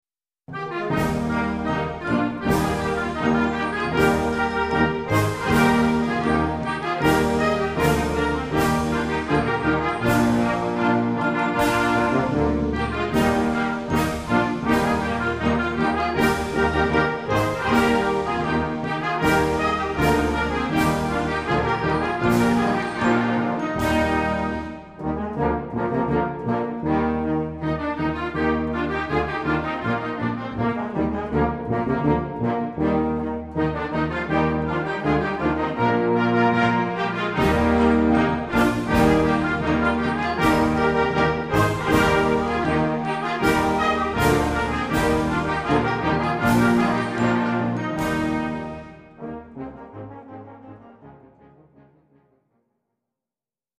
Concert Band ou Harmonie ou Fanfare